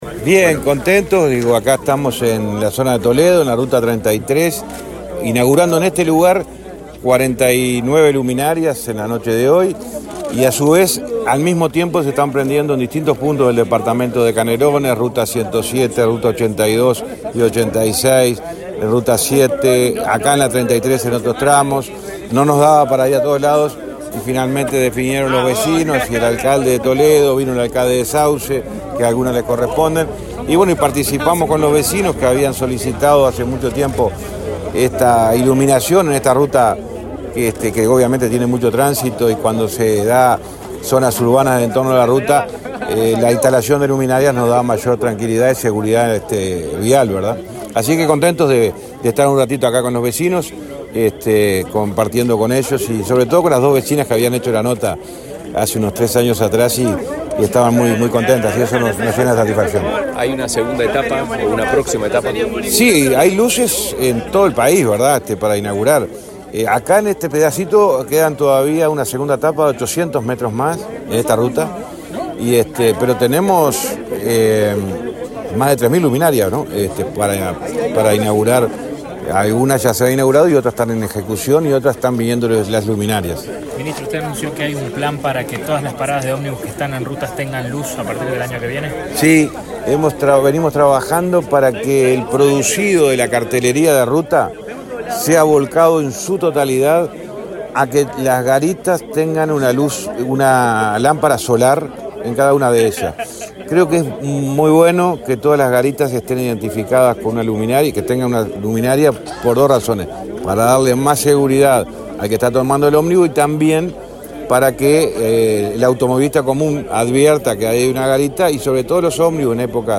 Declaraciones a la prensa del ministro de Transporte, José Luis Falero
Luego dialogó con la prensa.